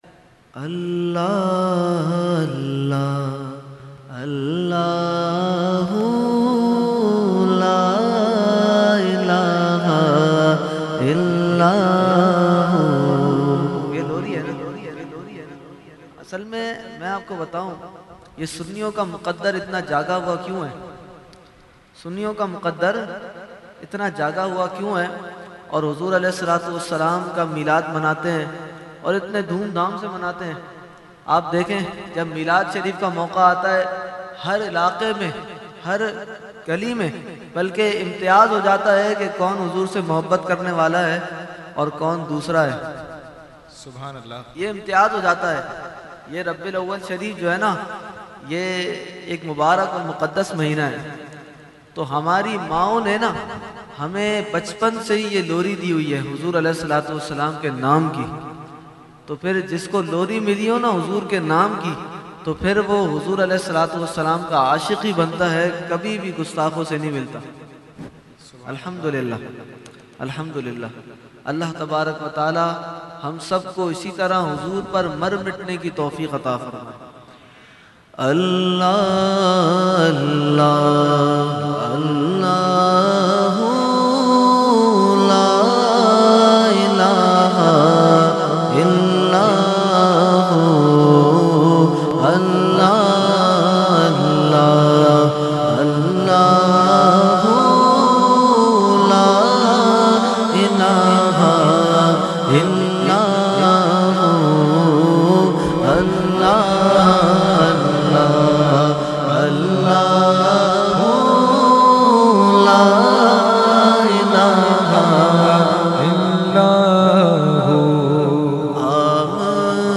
Mehfil e Meelad un Nabi ﷺ Held On 28 October 2020 At Jama Masjid Ameer Hamza Nazimabad Karachi.
Category : Naat | Language : UrduEvent : Mehfil e Milad Jamia Masjid Ameer Hamza 2020